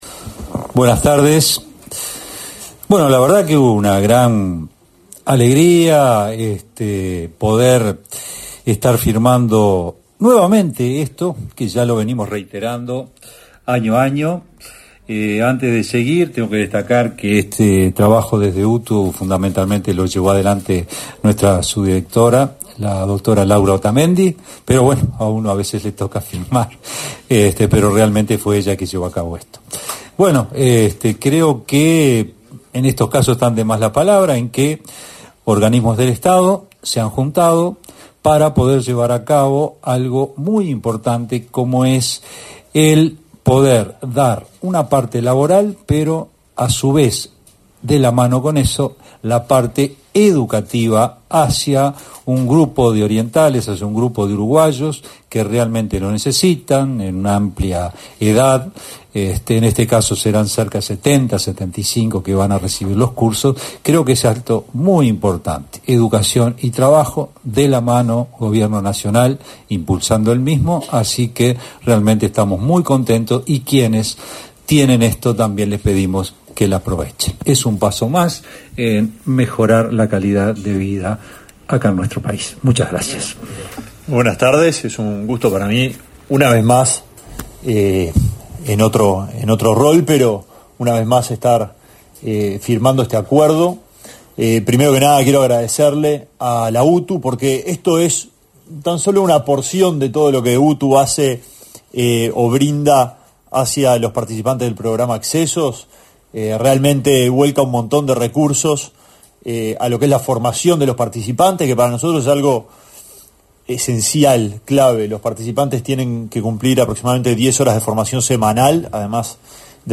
Acto de firma de convenio para favorecer formación de participantes del programa Accesos
El Ministerio de Transporte y Obras Públicas (MTOP), el de Desarrollo Social (Mides) y la UTU firmaron, este 27 de mayo, un acuerdo que fomenta la formación en oficios de los participantes del programa Accesos. Asistieron al evento los titulares de las referidas carteras, José Luis Falero y Alejandro Sciarra, y el director de la UTU, Juan Pereyra.